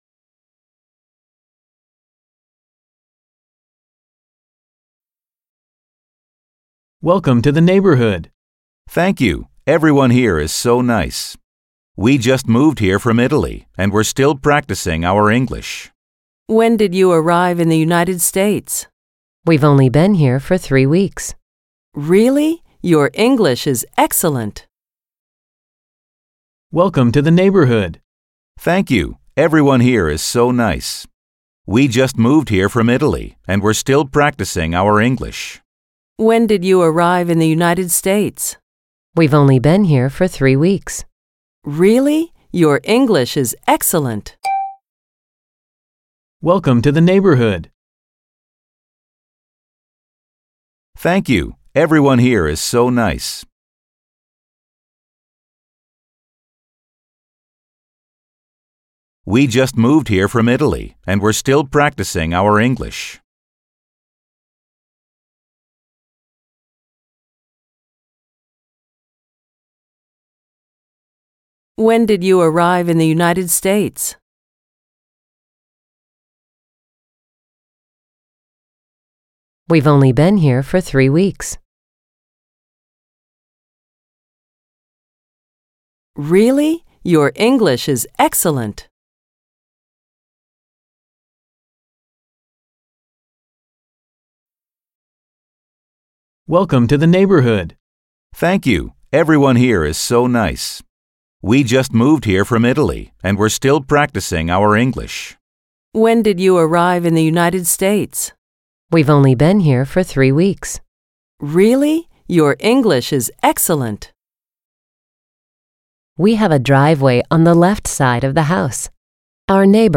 21_-_Lesson_4_Conversation.mp3